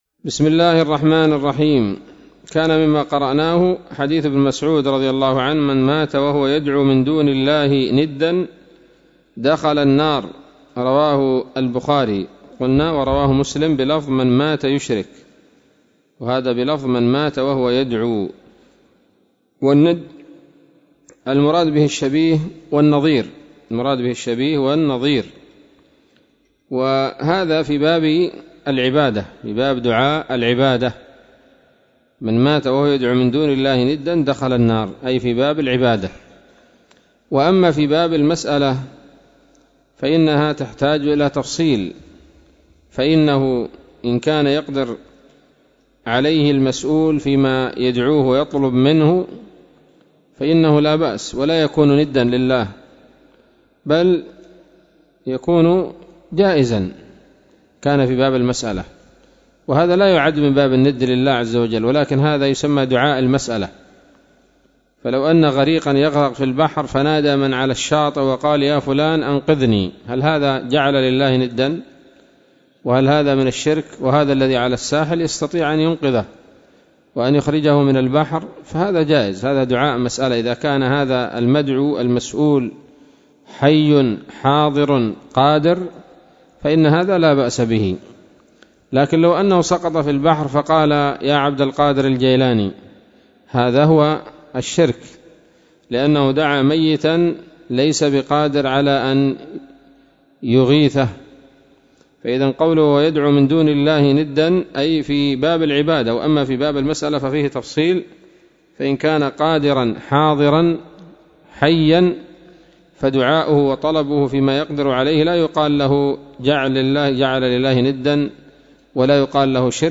الدرس العاشر من كتاب التوحيد للعام 1441هـ